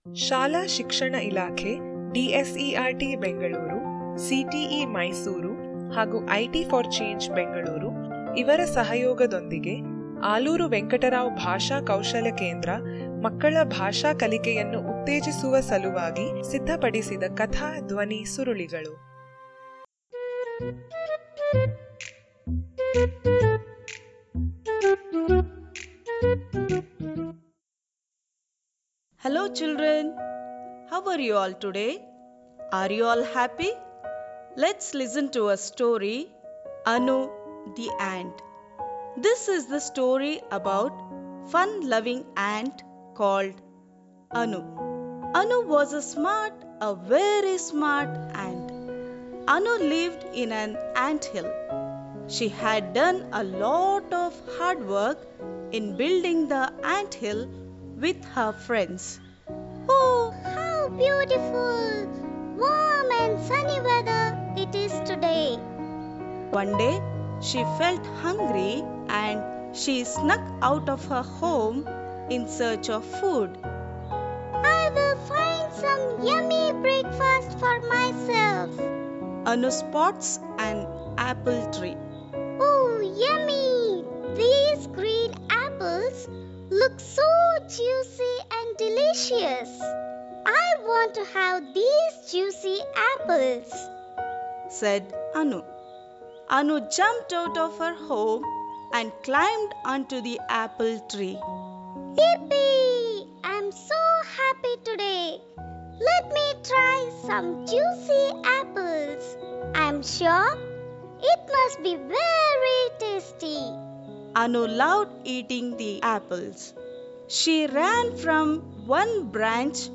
ANU THE ANT - AUDIO STORY ACTIVITY PAGE